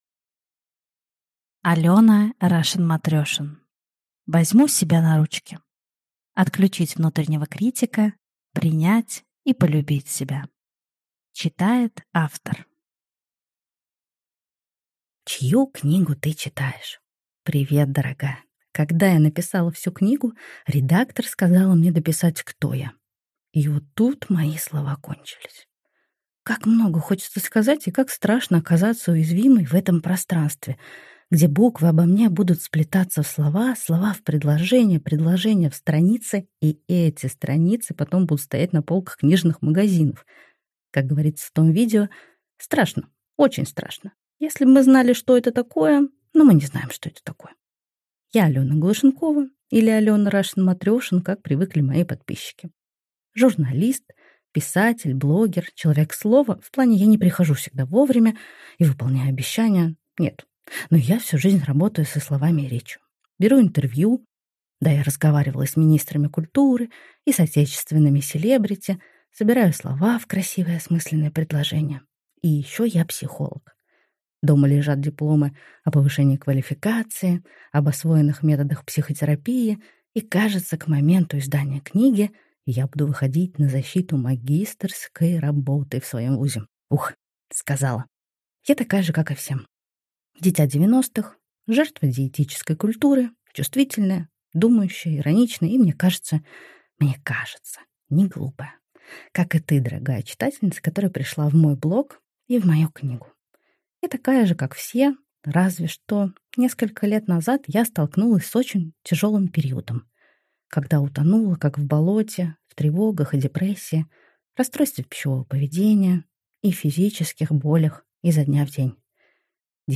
Аудиокнига Возьму себя на ручки.